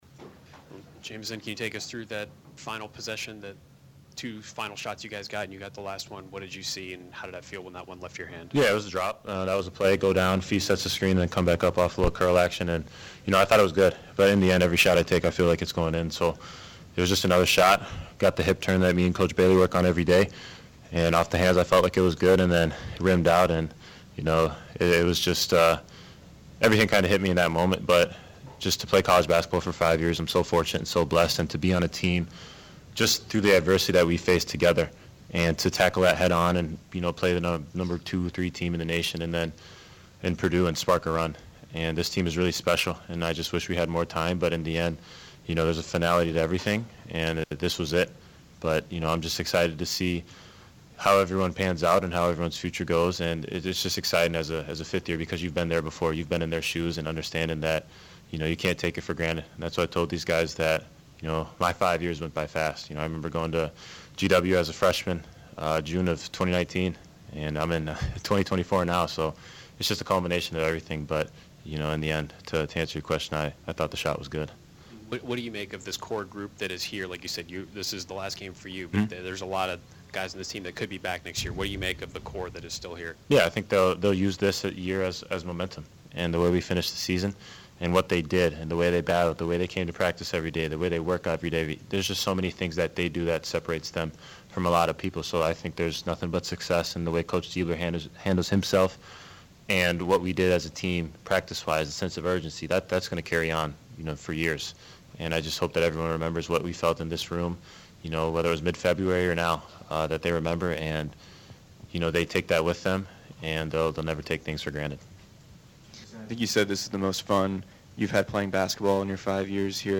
Media Press Conference